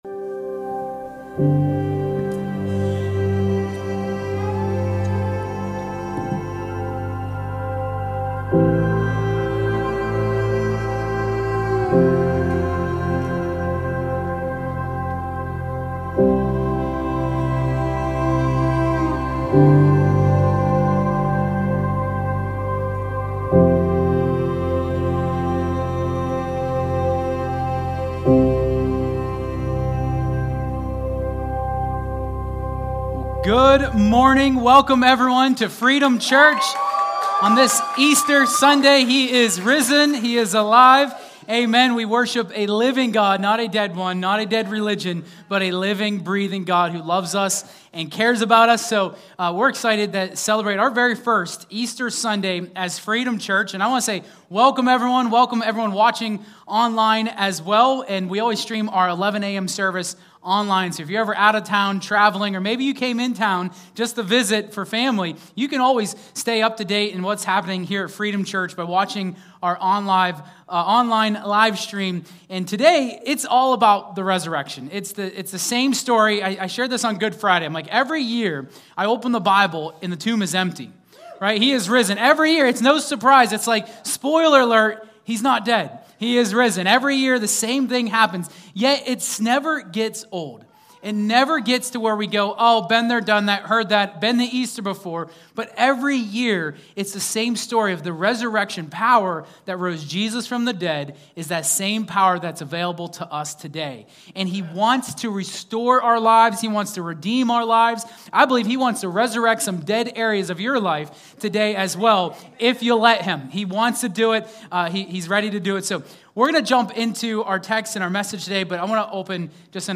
2026 Easter Sunday Service Explore the profound significance of Jesus Christ's resurrection